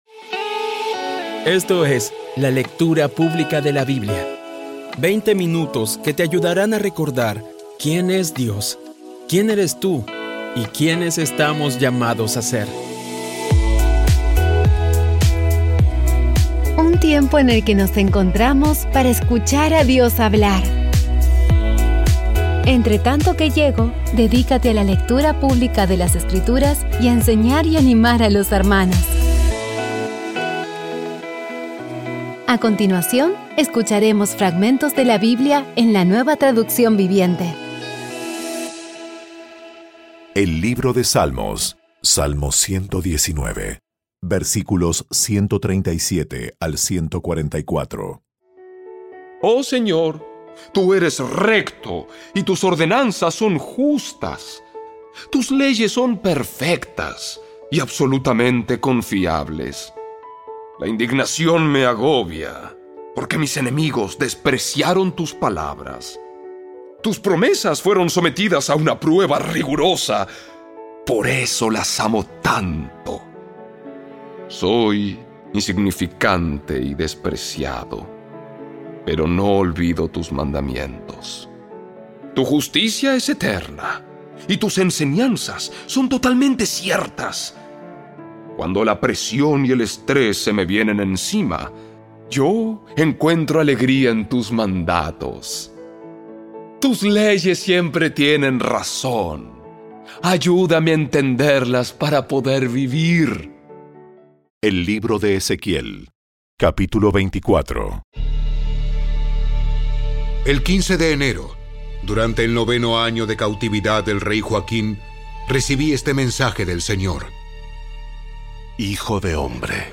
Audio Biblia Dramatizada Episodio 313
Poco a poco y con las maravillosas voces actuadas de los protagonistas vas degustando las palabras de esa guía que Dios nos dio.